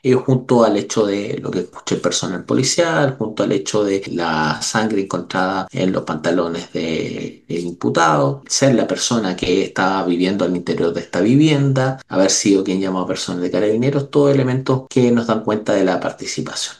El fiscal de Calbuco, Marcelo Maldonado, ante el Tribunal de Letras y Garantía, expuso que el imputado realizó una limpieza en la casa tras el crimen.